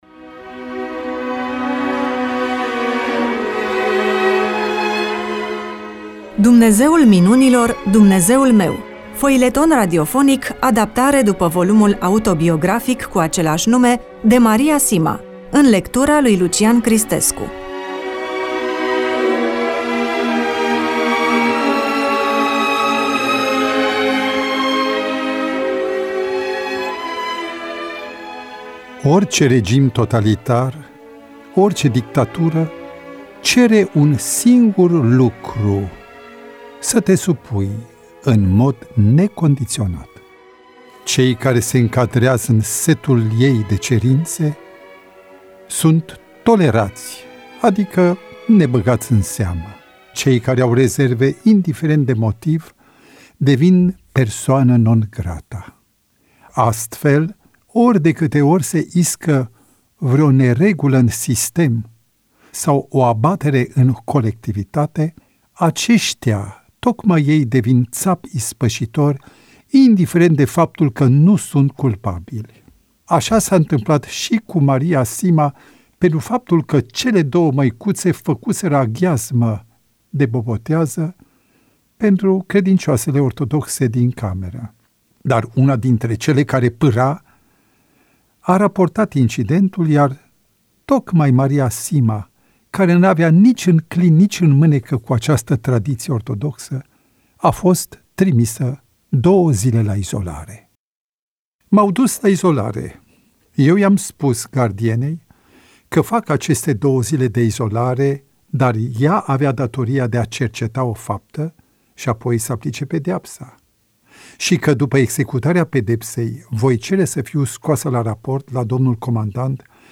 EMISIUNEA: Roman foileton DATA INREGISTRARII: 23.01.2026 VIZUALIZARI: 18